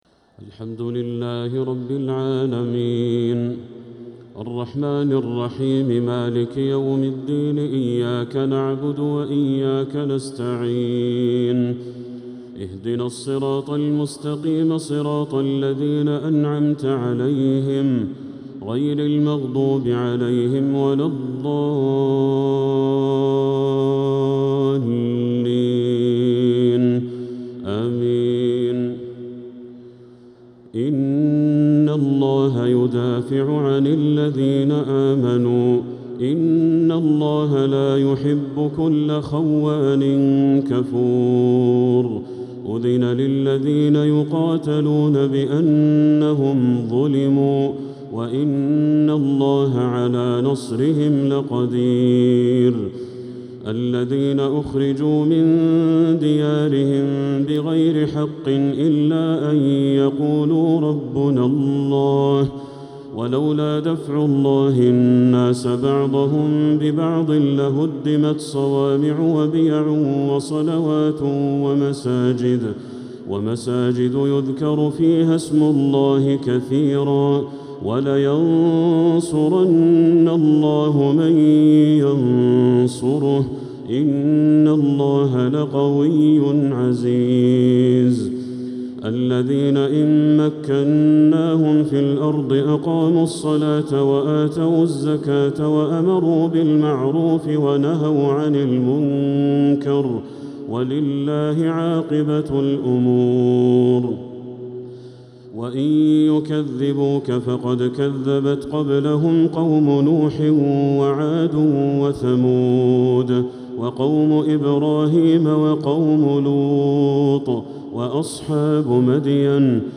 تراويح ليلة 22 رمضان 1447هـ من سورتي الحج (38-78) و المؤمنون (1-50) | Taraweeh 22nd night Ramadan 1447H Surah Al-Hajj and Al-Muminoon > تراويح الحرم المكي عام 1447 🕋 > التراويح - تلاوات الحرمين